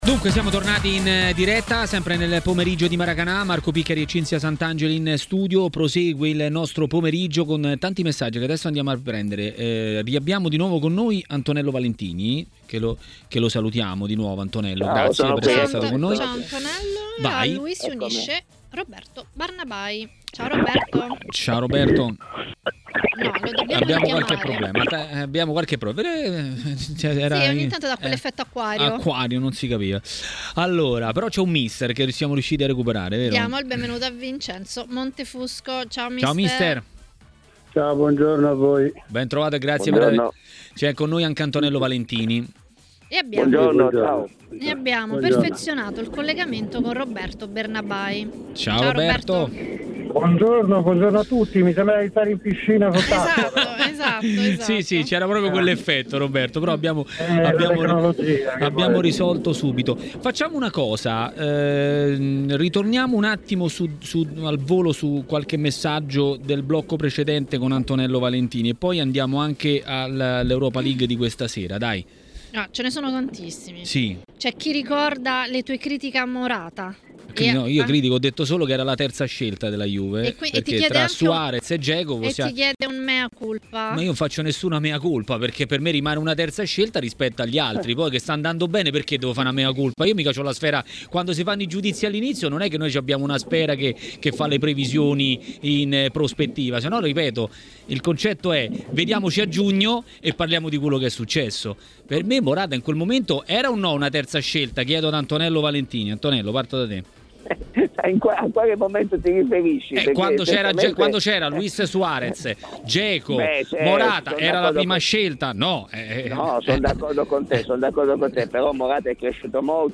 Mister Vincenzo Montefusco a TMW Radio, durante Maracanà, ha commentato le italiane in Europa League e non solo.